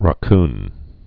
(rŏ-kn)